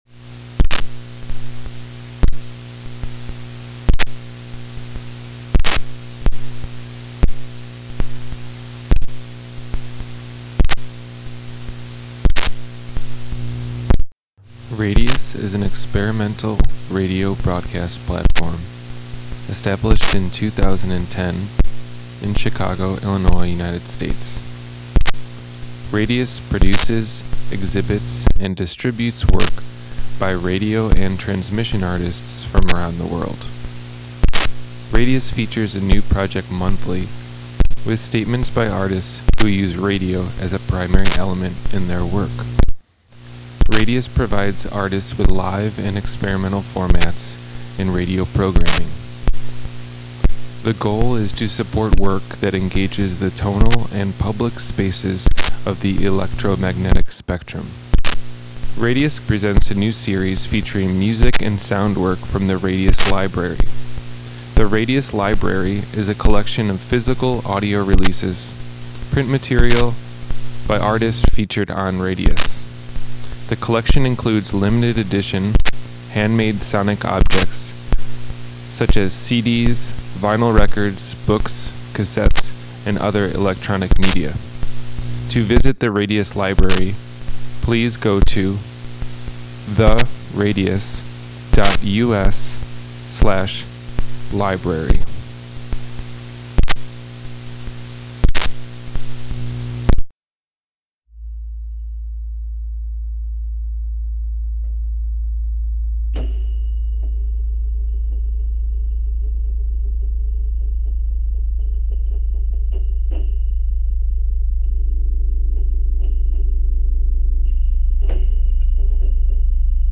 Radius is an experimental broadcast platform located in Chicago, IL, USA. Radius features a new project each month with statements by artists who use radio as a primary element in their work. Radius provides artists with live and experimental formats in radio programming.